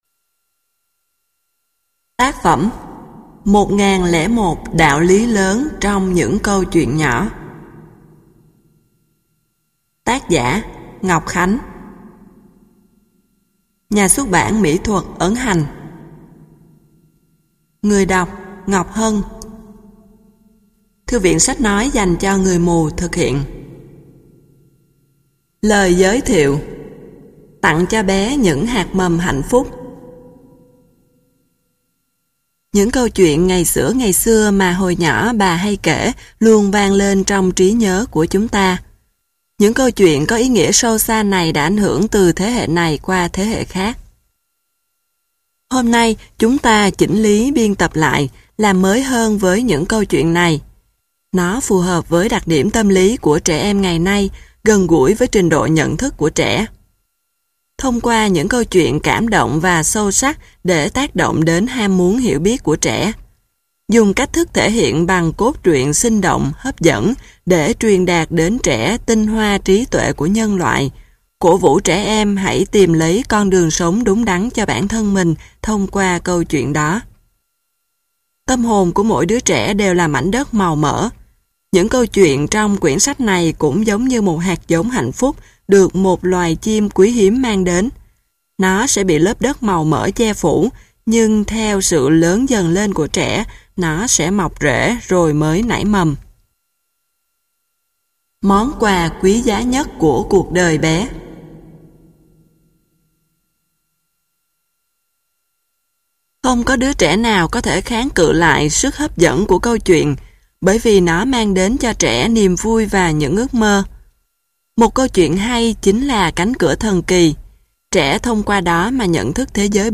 Sách nói 1001 Đạo Lý Lớn Trong Những Câu Chuyện Nhỏ